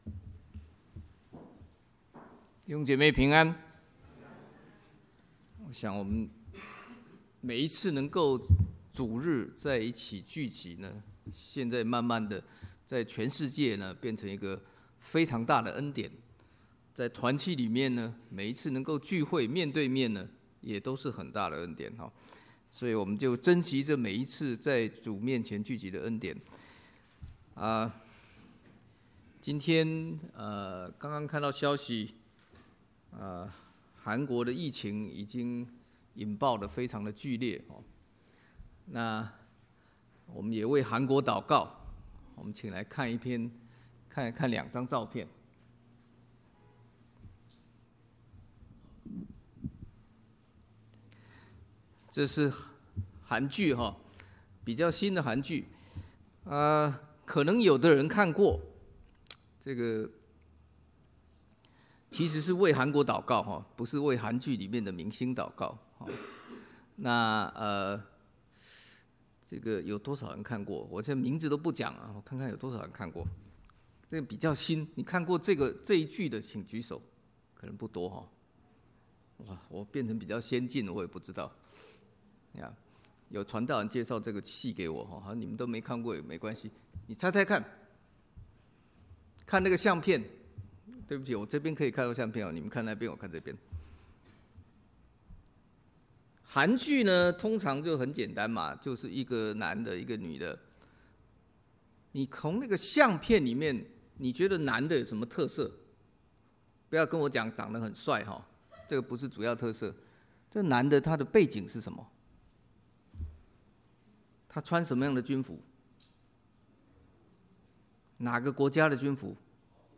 華埠國語堂